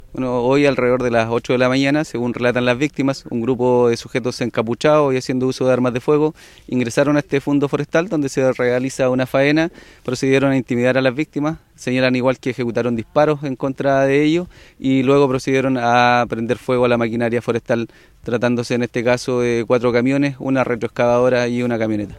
El fiscal de Lautaro, Enrique Vásquez, junto con precisar que se investiga los delitos de incendio, porte y tenencia ilegal de arma de fuego y disparos injustificados, entregó detalles de cómo operó este grupo armado.
cu-fiscal-enriquie-vasquez.mp3